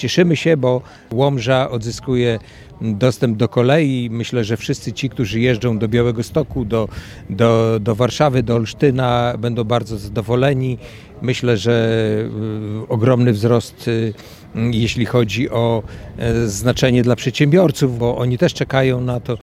Członek zarządu województwa podlaskiego Jacek Piorunek przypomniał, że samorząd województwa pokryje część kosztów inwestycji.